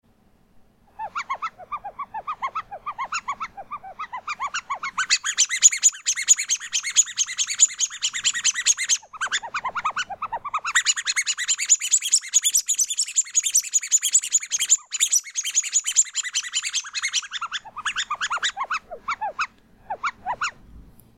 Achso, und quietschen kann sie auch, meine kleine Gummieule, das klingt dann so: